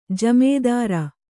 ♪ jamēdāra